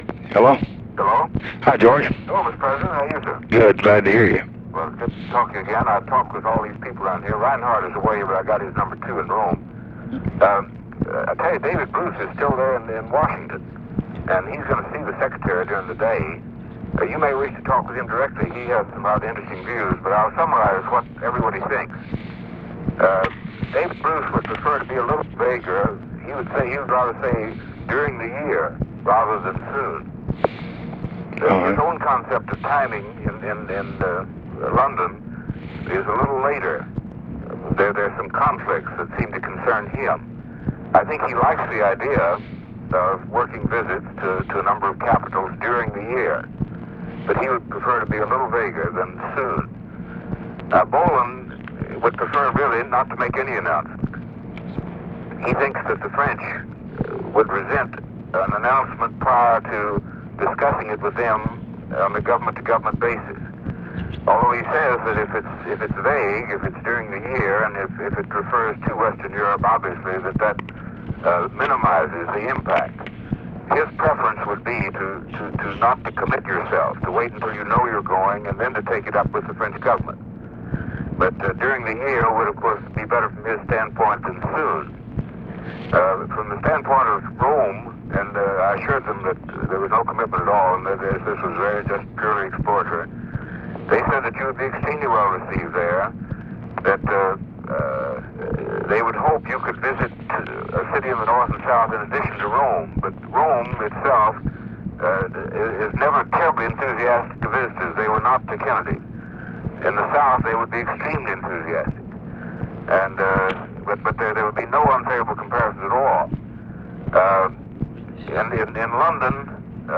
Conversation with GEORGE MCGHEE, January 4, 1965
Secret White House Tapes